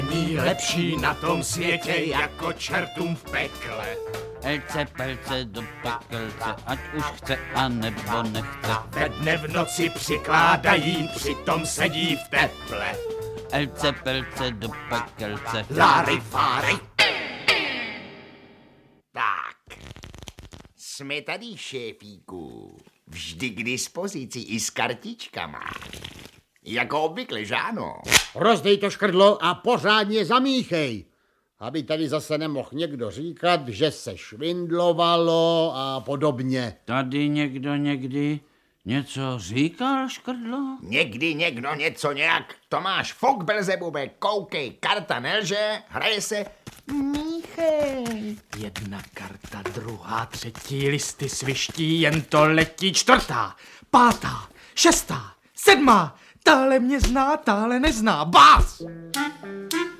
Audiobook
Read: Jan Kanyza